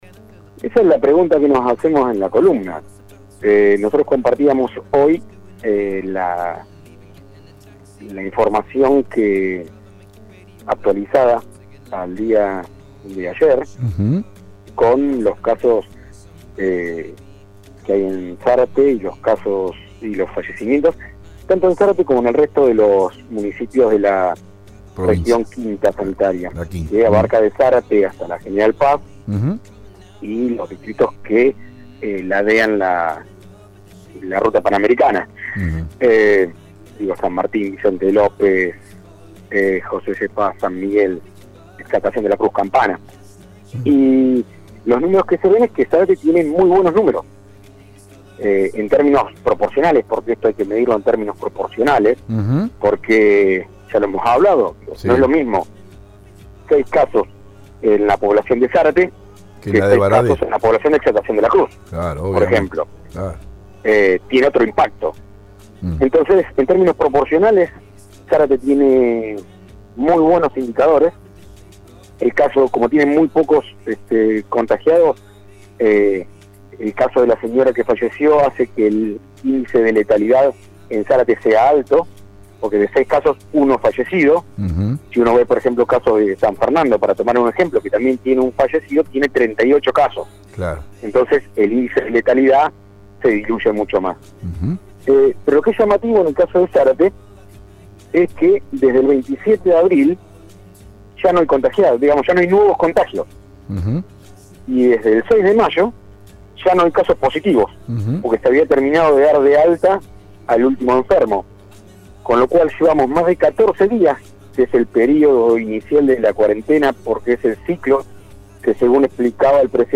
CON ZETA 1973: El resumen completo de los cuatro protagonistas del programa en radio EL DEBATE, del pasado viernes - EL DEBATE